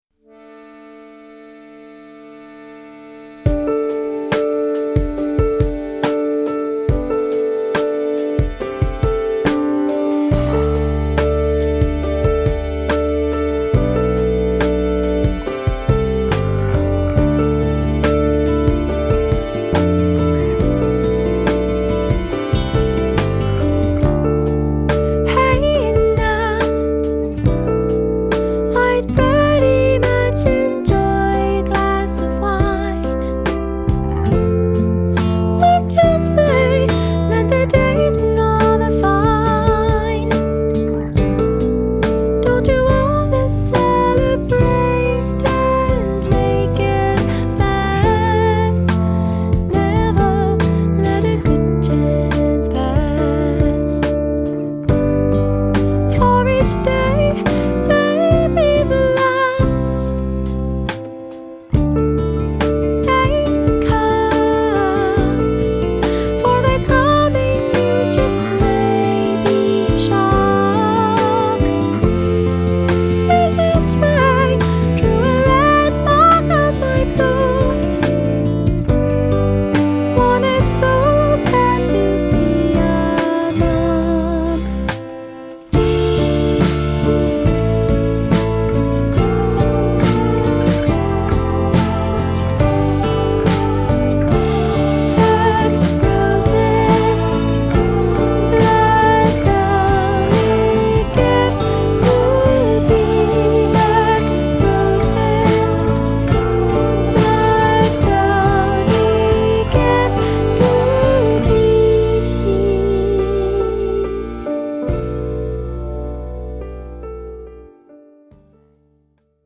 ブリリアントなメロディにキュートな歌声を乗せたドリーミー傑作
piano
前作に比べてこちらの方がしっとりとしたサウンドになったように感じられます。
メロディアスで耽美　アイボリーをシンフォニックハードにした感じでは失礼かな。